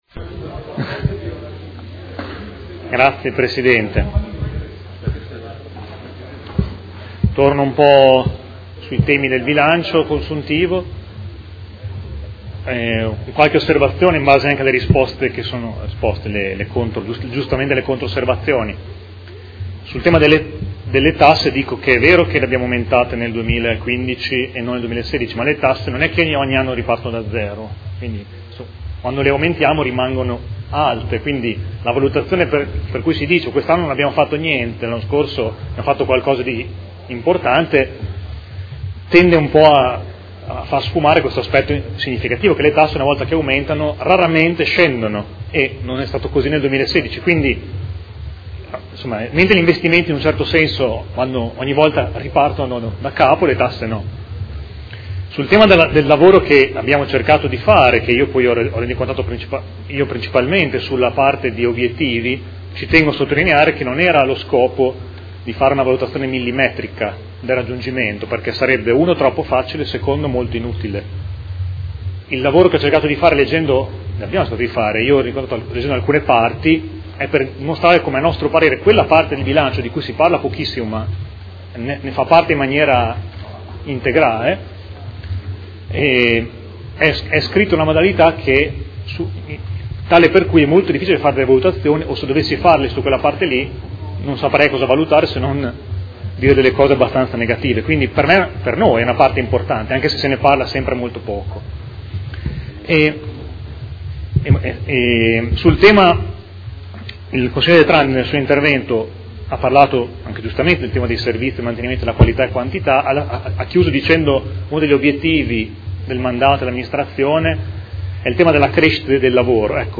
Seduta del 28/04/2016. Proposta di deliberazione: Rendiconto della gestione del Comune di Modena per l’esercizio 2015 – Approvazione. Dichiarazioni di voto